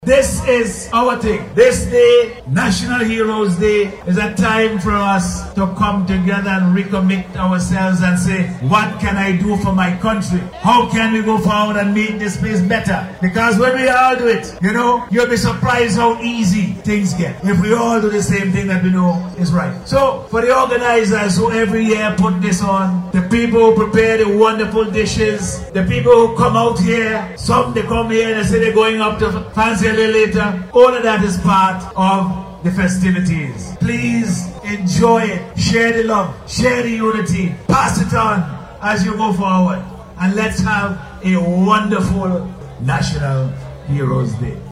The Prime Minister was addressing the gathering at the Greiggs Heroes Day Festival, noting that the country is stronger and more can be achieved, when everyone works together.